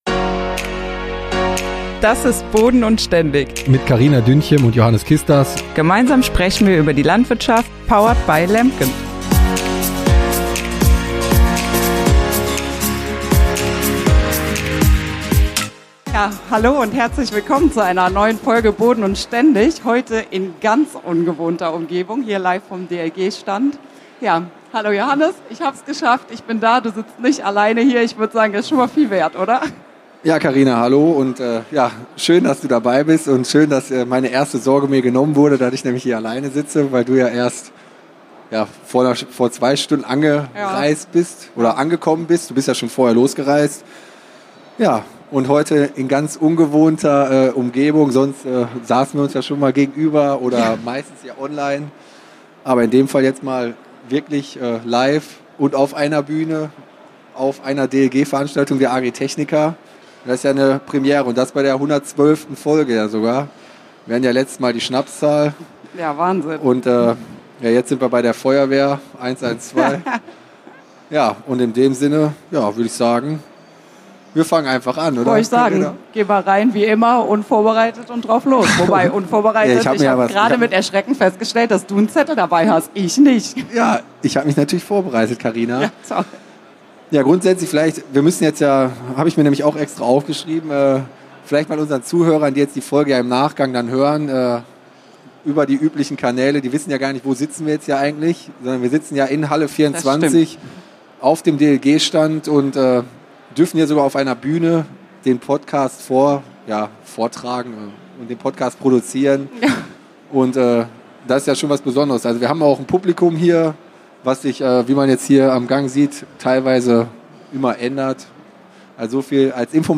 #112 – Live auf der Agritechnica: Messeneuheiten & Messealltag ~ Boden&ständig Podcast
Und das diesmal nicht vom LEMKEN Stand sondern direkt auf der offiziellen DLG-Bühne!